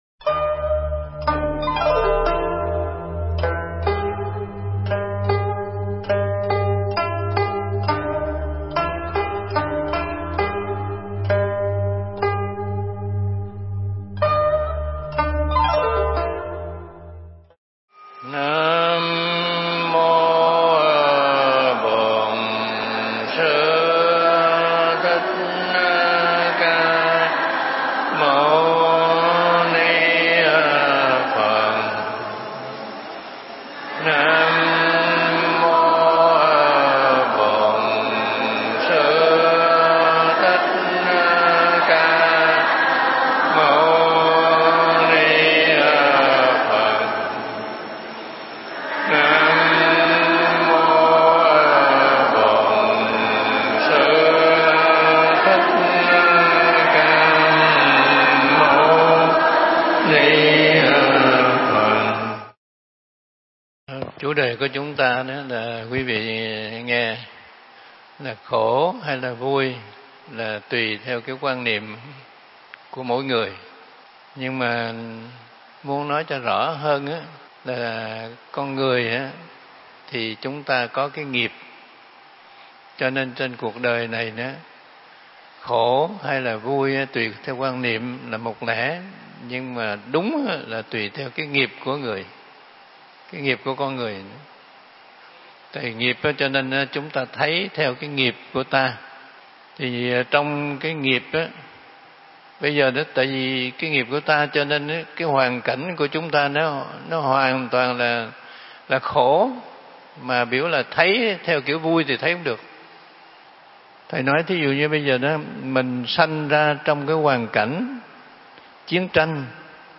Mp3 Pháp Thoại Khổ Vui Tùy Theo Cách Nhìn – Hòa Thượng Thích Trí Quảng giảng tại chùa Phổ Quang (Quận Tân Bình, HCM), ngày 1 tháng 10 năm 2017